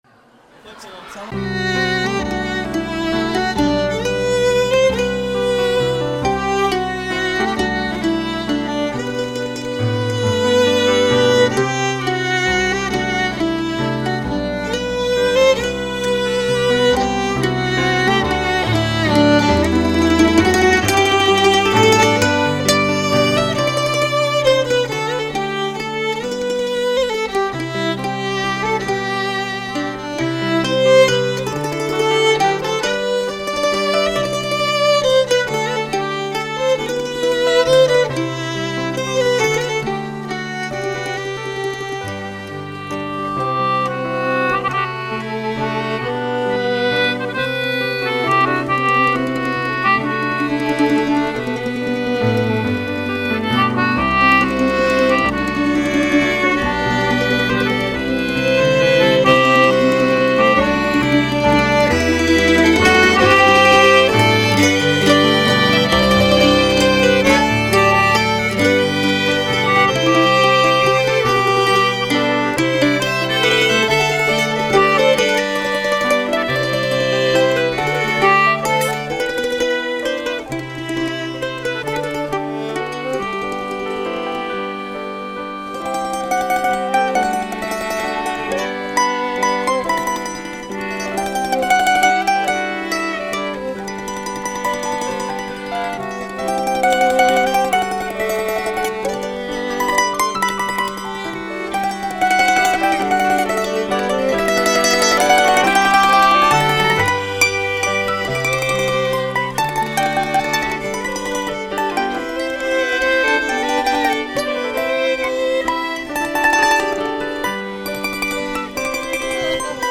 mp3 - live version) (pdf)
that my waltz